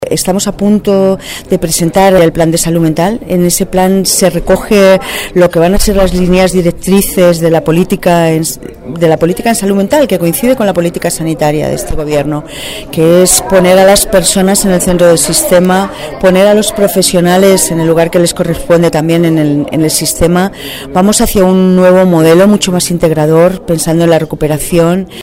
Directora general de Planificación Sanitaria sobre el nuevo Plan de Salud Mental